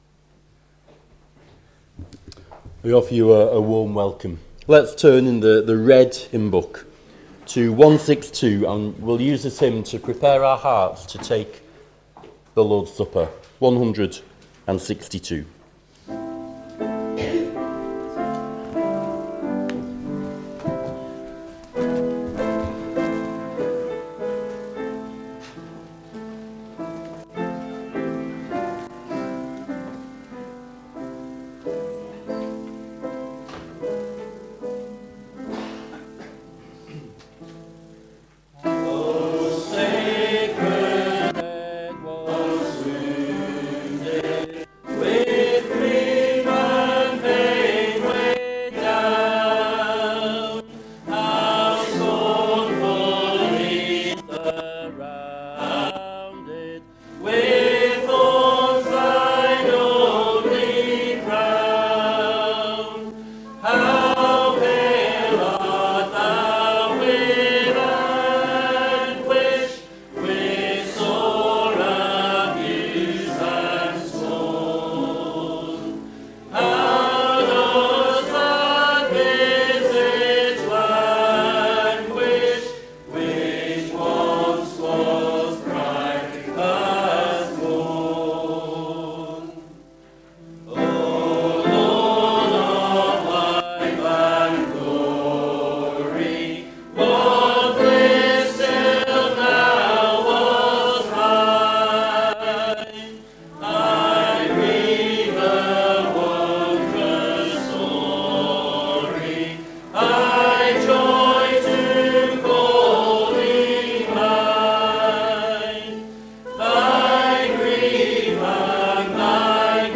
Below is audio of the full service.
2025-10-19 Morning Worship If you listen to the whole service on here (as opposed to just the sermon), would you let us know?